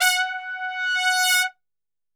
F#3 TRPSWL.wav